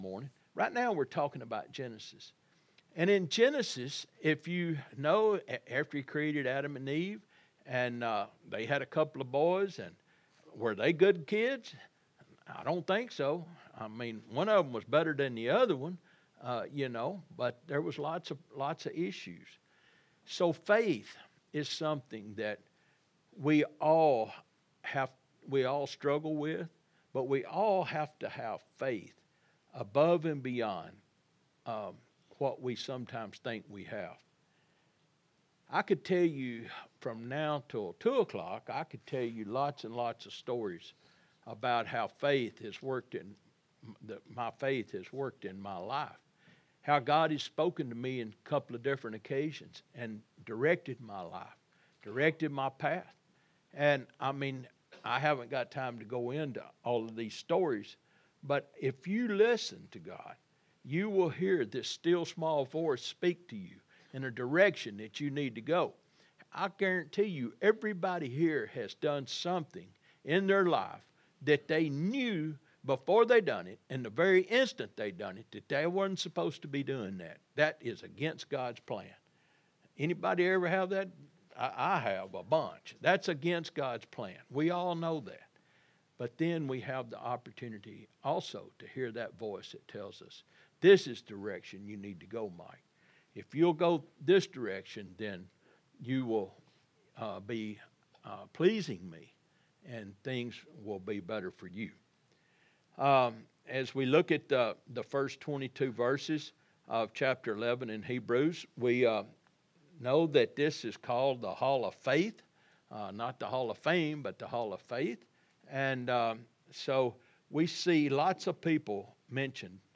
11-3-24-Sermon.mp3